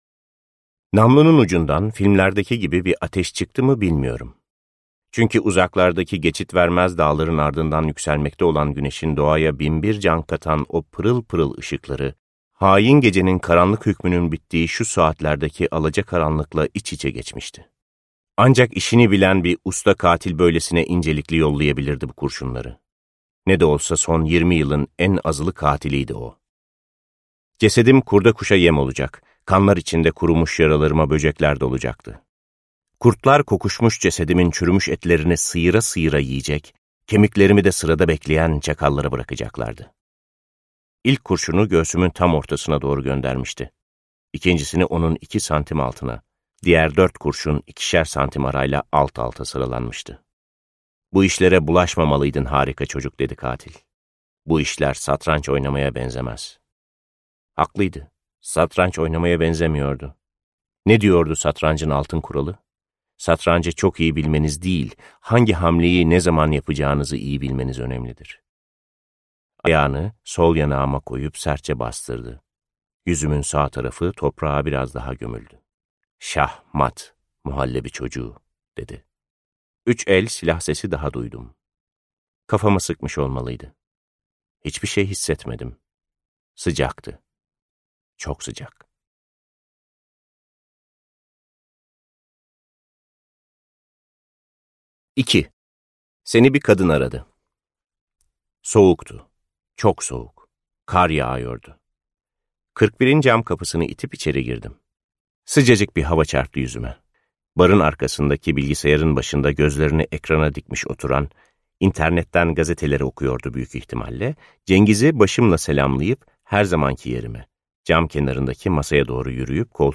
Seslendiren